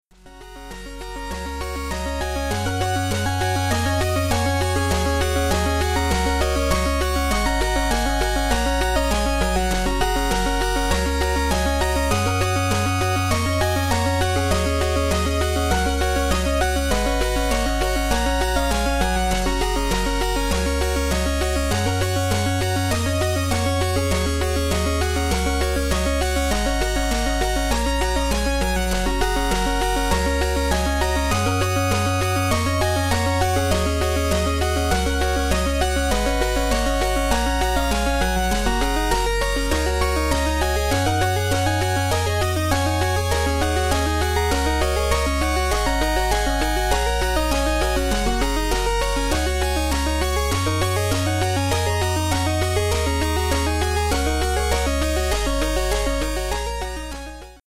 8-bit test
I suck at Famitracker, but I am running some tests to get better with it.
Game Music